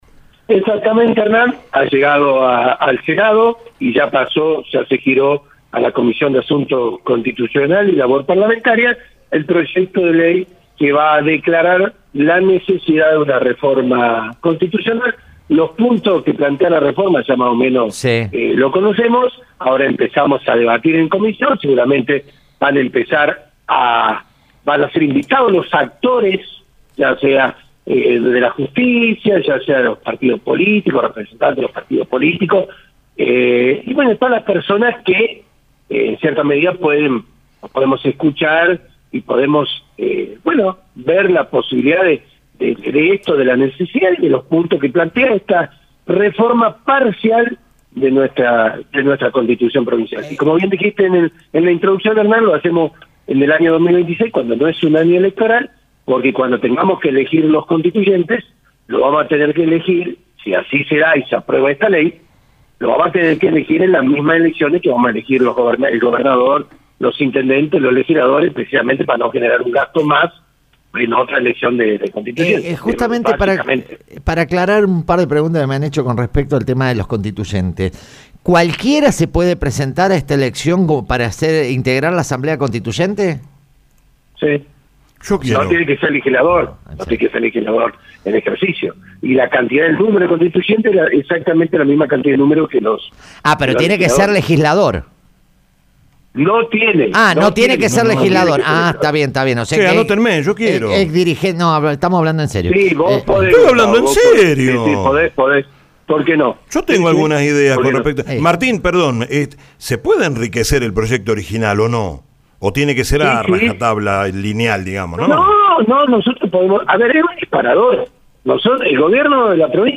En una entrevista exclusiva para Radio La Bomba, el senador Martín Olivero explicó por qué es noticia que se inicie este debate y cuáles son los cambios más importantes que se proponen: